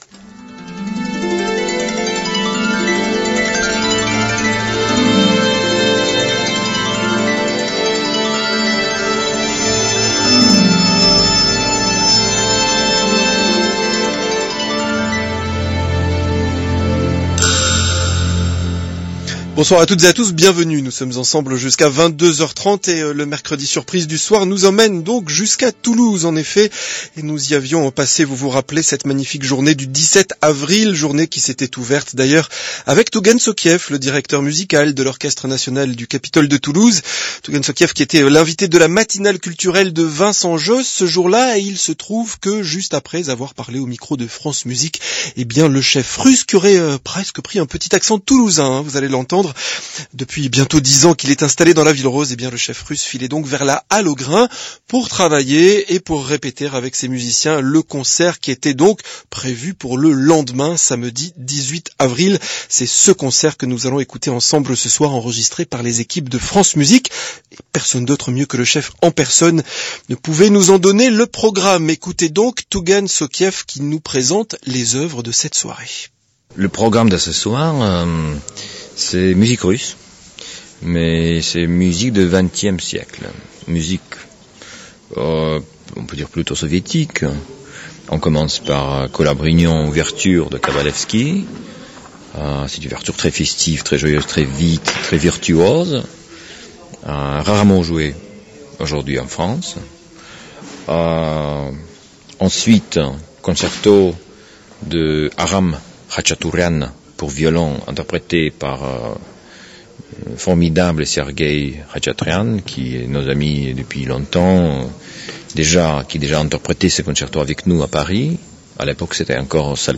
Sergey Khachatryan With Tugan Sokhiev And The Capitol of Toulouse Orchestra In Concert 2015 - recorded live at The Halle aux Grains, Toulouse.
Sergey Khachatryan, violin – Capitol Of Toulouse Orchestra – Tugan Sokhiev, Conducter – April 15, 2015 – Radio France Musique –
Over to France this week for a concert, broadcast live from Toulouse at the Halle aux Grains on April 15, 2015 featuring the Capitol of Toulouse Orchestra led by Music Director Tugan Sokhiev and featuring Sergey Khachatryan, violin in music of Kabalevsky, Khachatourian and Stravinsky.